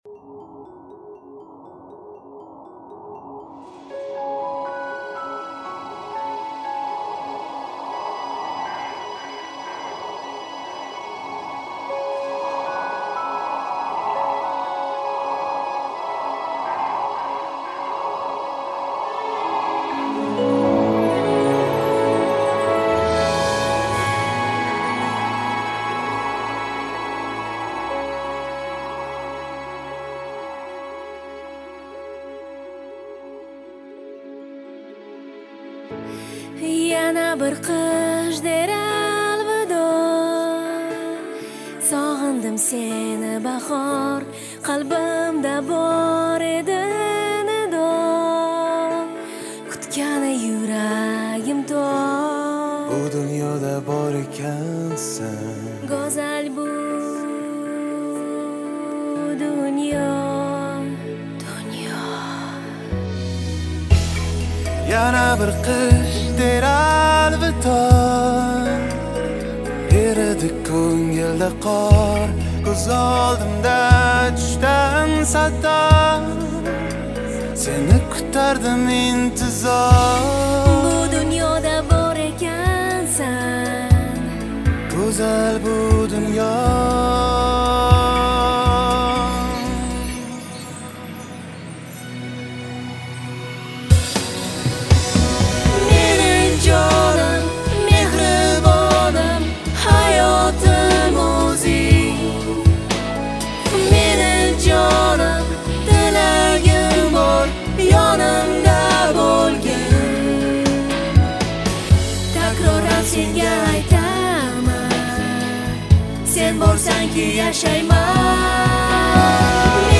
• Жанр: New Uzb / Узбекские песни